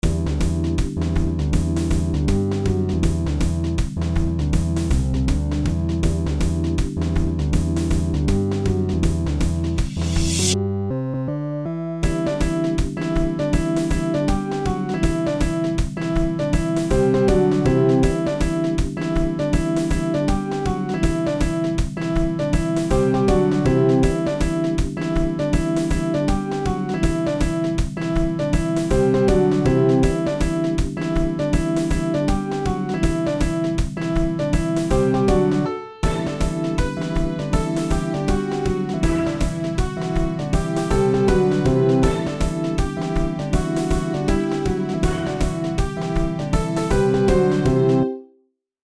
Electric Piano
SGM Organ 2 SGM Drum Kits SGM Synth Brass 1 SGM Orchestra Hit
Grand Piano